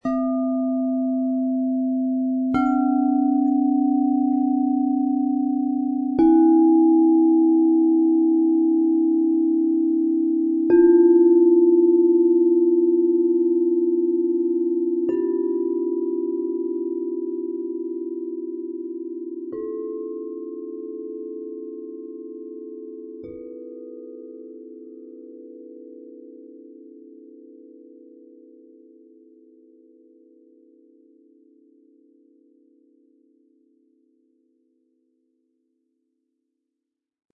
Kristall-Klangschalen Chakrenset im 7er Set mit Klöppel, 432 Hz
Kristallschalen mit ihren intensiven, sphärischen und tragenden Klängen und Schwingungen wirken besonders intensiv auf unsere Chakren.